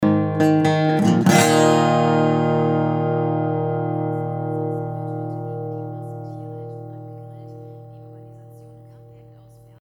Radioempfang auf Audioaufnahmen
Die Aufnahmen sind soweit auch super, aber uns ist als alles fertig war aufgefallen, dass an sehr leisen Stellen Radio zu hören ist, was natürlich SCH**** ist...
Alle Spuren wurden über Mikro - Tascam US1800 - Pc aufgenommen, einzig ungewöhnliches könnte sein, dass die XLR Kabel recht lang sind ( glaube maximal 15 bis 20 Meter ) Da Regie- und Aufnahmeraum voneinander getrennt sind...
Im Anhang die auffälligste Stelle...